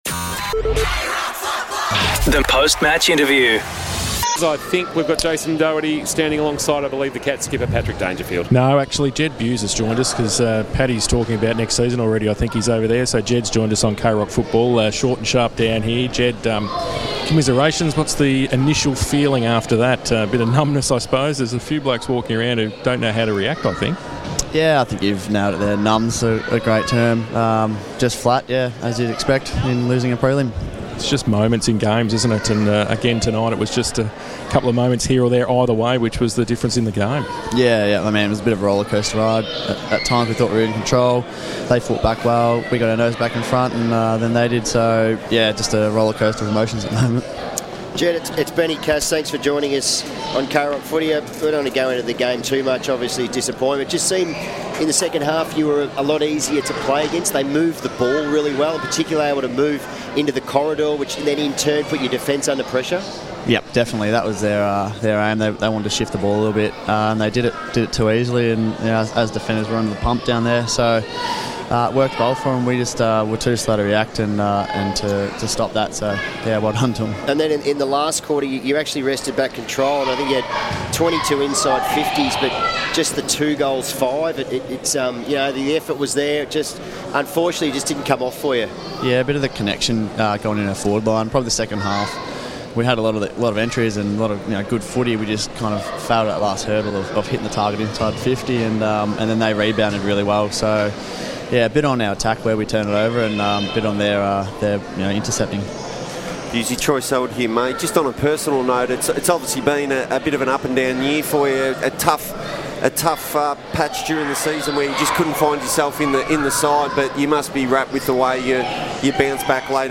2024 - AFL - Preliminary Final - Geelong vs. Brisbane: Post-match interview - Jed Bews (Geelong Cats)